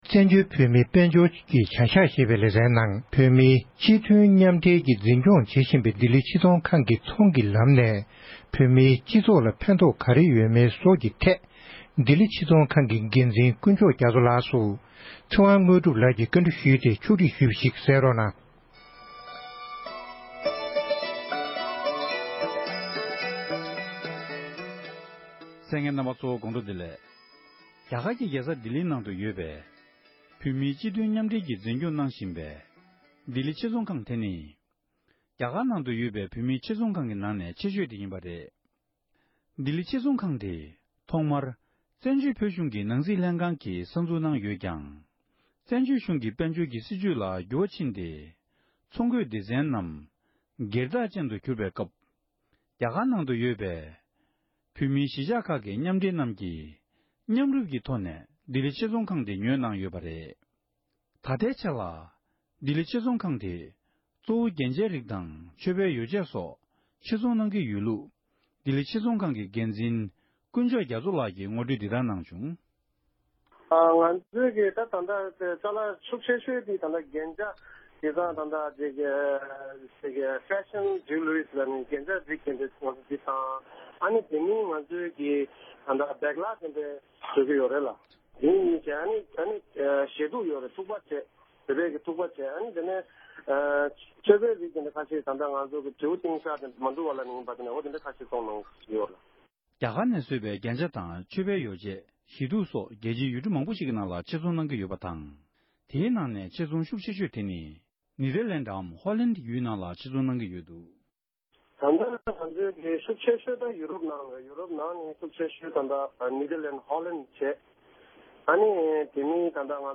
གནས་འདྲི་ཞུས་ཏེ་ཕྱོགས་བསྒྲིགས་ཞུས་པར་གསན་རོགས༎